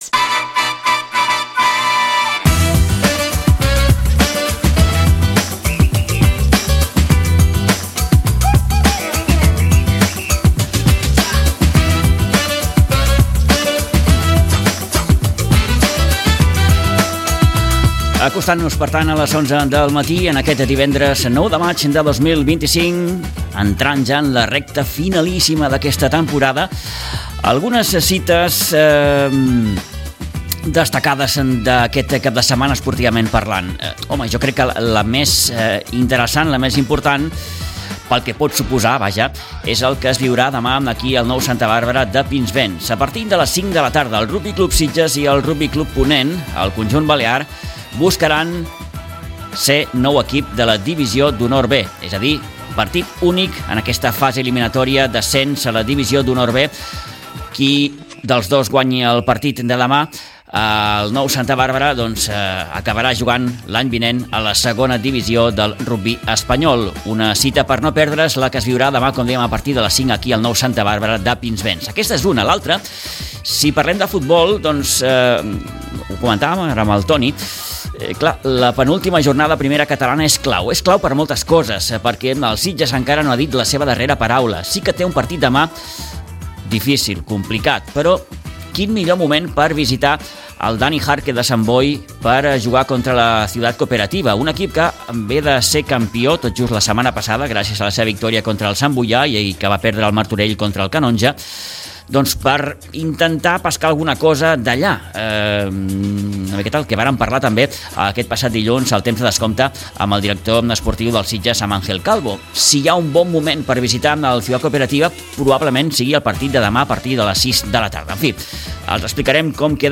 Amb Jaume Monasterio, regidor d’esports de l’Ajuntament de Sitges, parlem de la 2ª Setmana de l’Esport que arrenca aquest cap de setmana amb la voluntat de mostrar i donar a conèixer tota l’activitat esportiva.